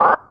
sci-fi_alarm_warning_loop_02.wav